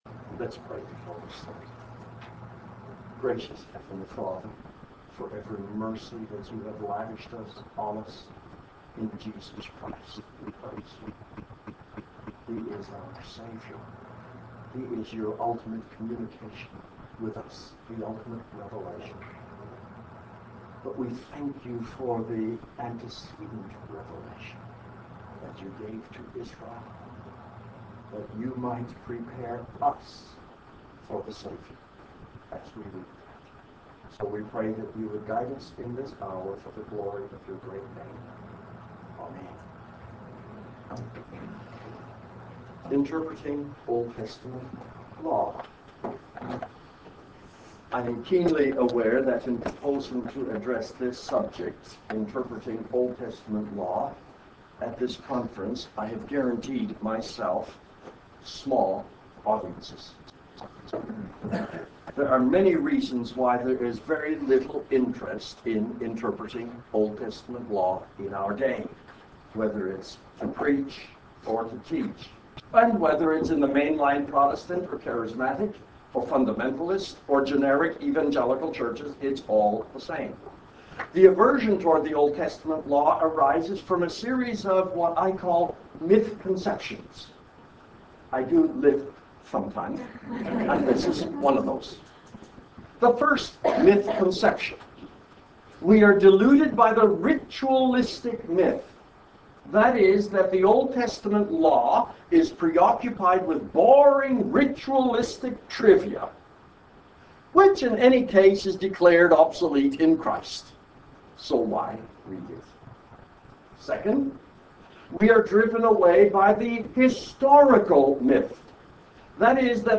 Breakout Session